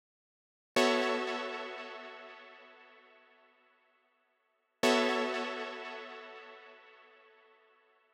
12 Synth PT3.wav